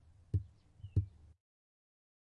游戏声音 " 攀登台阶
描述：脚步声上升的步骤
Tag: 散步 脚步声 脚步声